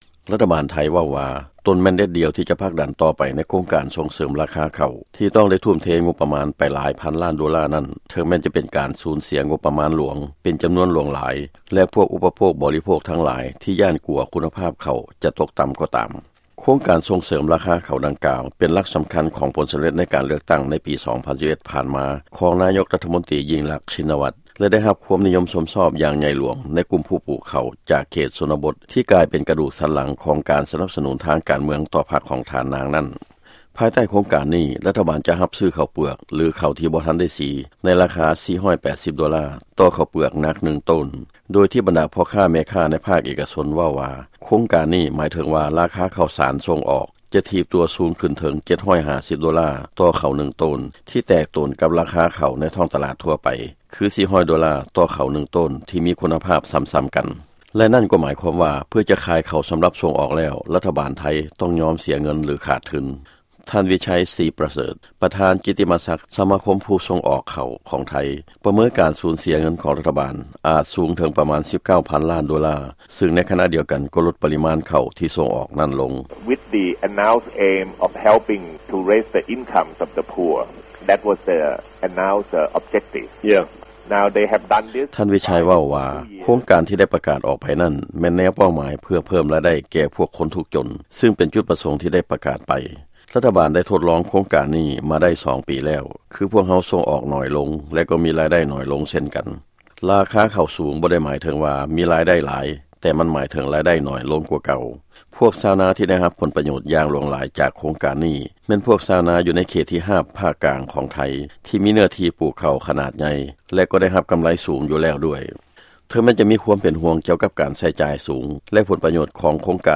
ຟັງລາຍງານ ກ່ຽວກັບເຂົ້າ ໃນໄທ